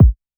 Lookin Exotic Kick.wav